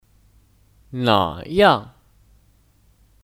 哪样 Nǎyàng (Kata ganti): Seperti apa